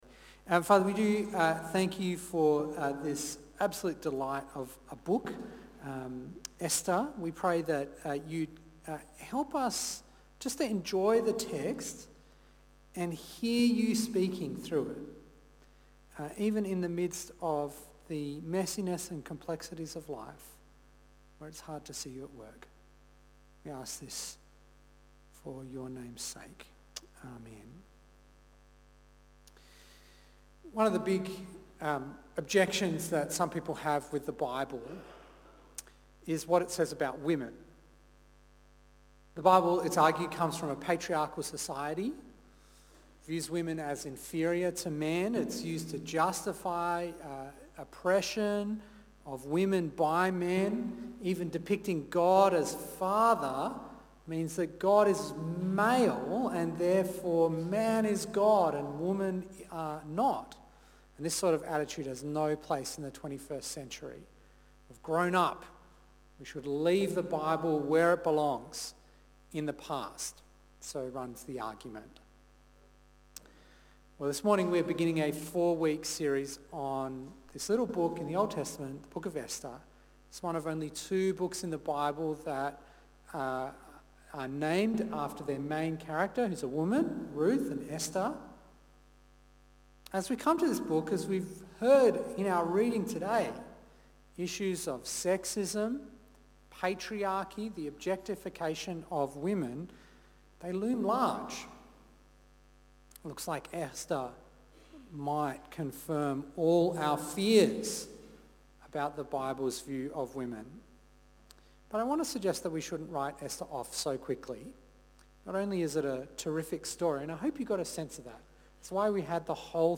Esther 1-2 Preacher